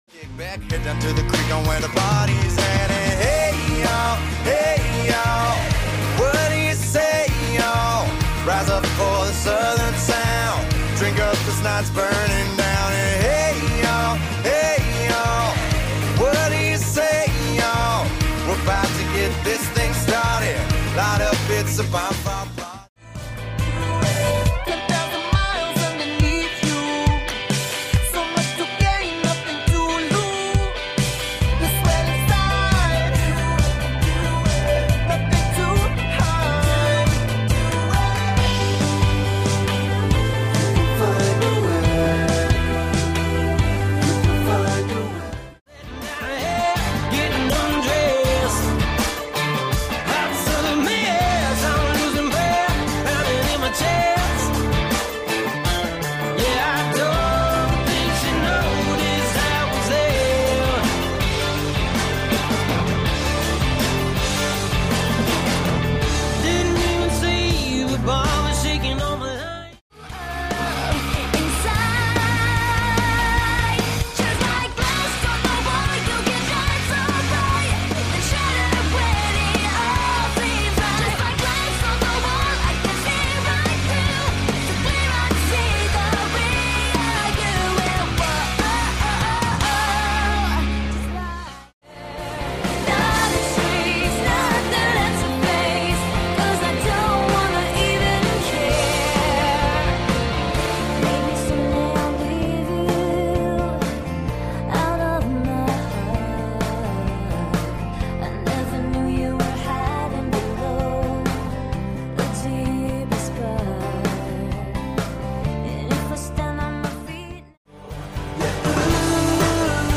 styl - pop/pop rock/ballads